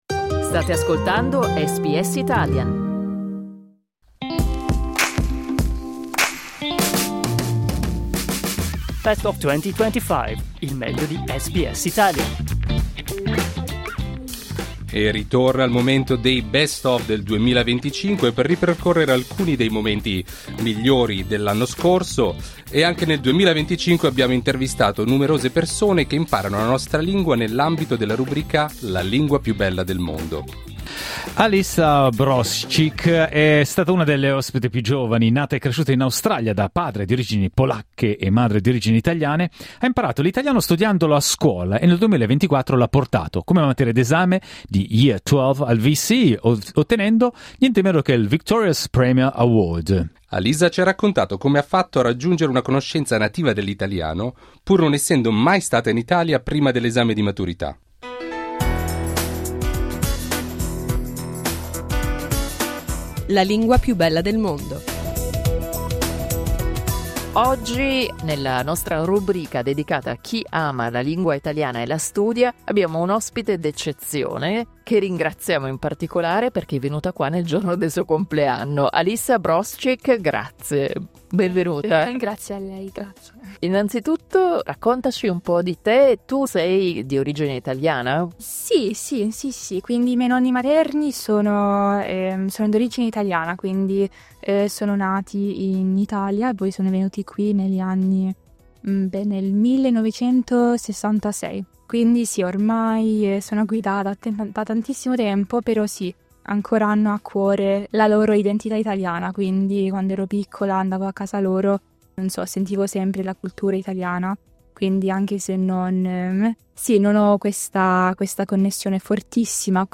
Clicca sul tasto "play" in alto per ascoltare l'intervista integrale L'intervista originale